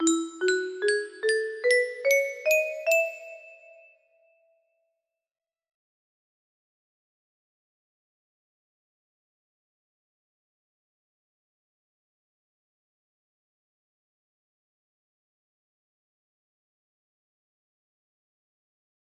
Scale beginning with G music box melody
Scale beginning with G